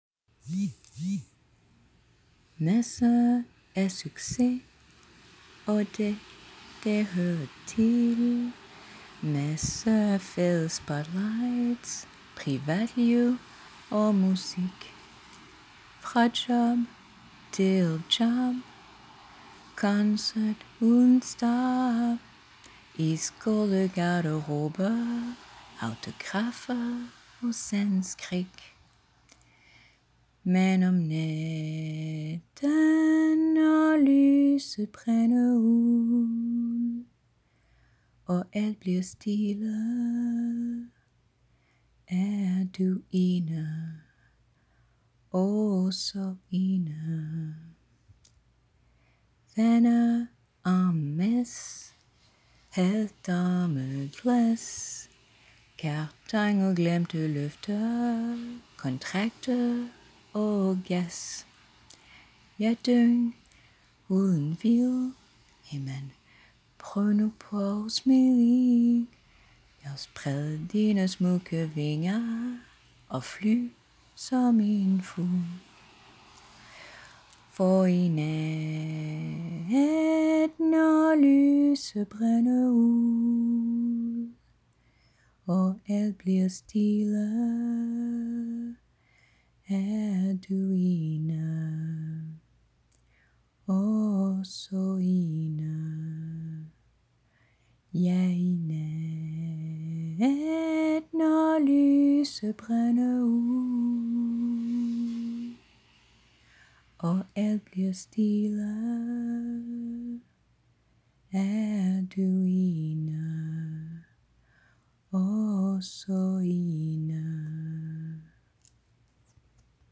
There is a little hiss in the background initially ….